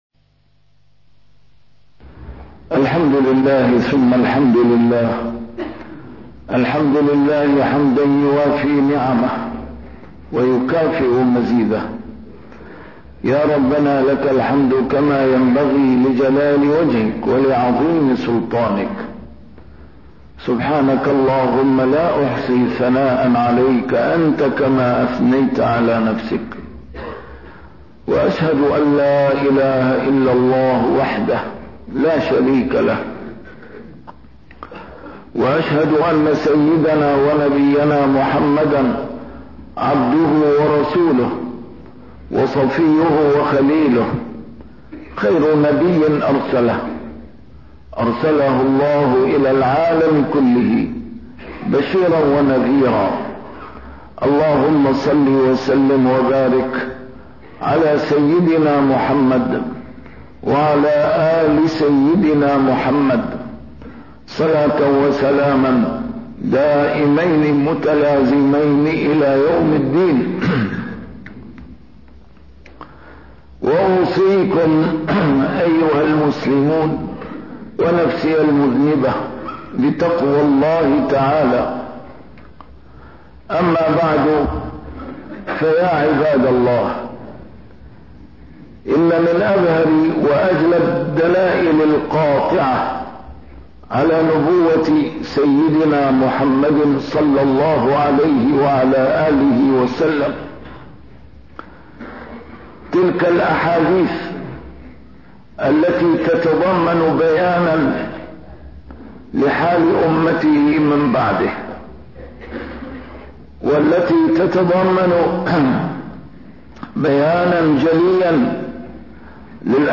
A MARTYR SCHOLAR: IMAM MUHAMMAD SAEED RAMADAN AL-BOUTI - الخطب - الإرهاب صناعة بريطانية تظهر بصورة إسلامية